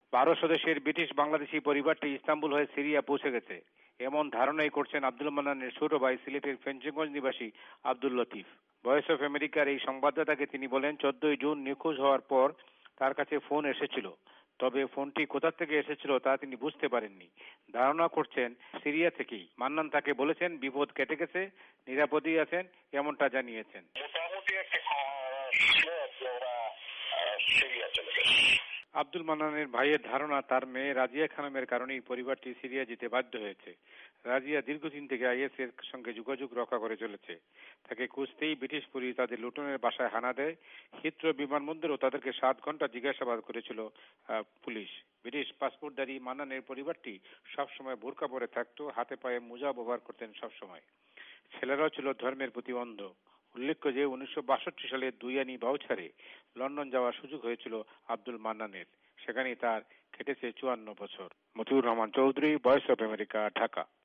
ঢাকা থেকে সংবাদদাতা